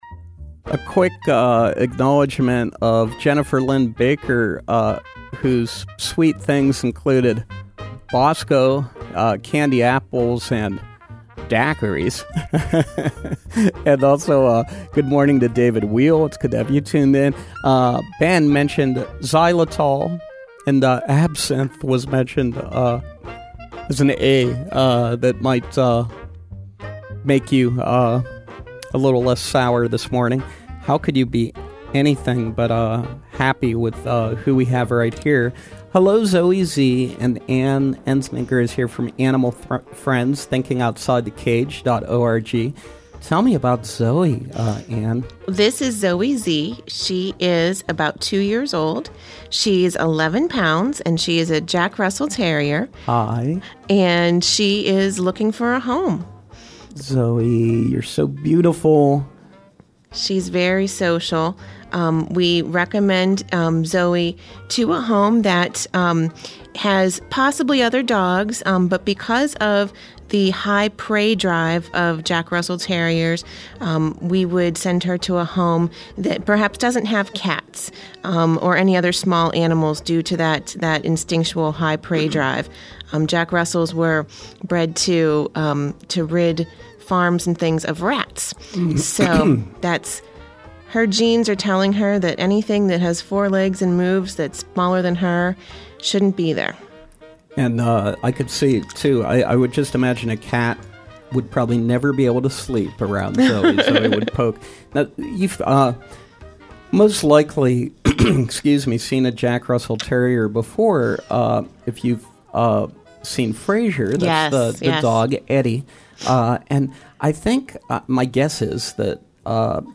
drops by our studios once again, bringing down a little puppy in hopes of finding it a home. If you’d like to adopt a cuddly little creature, Animal Friends is a great place to start!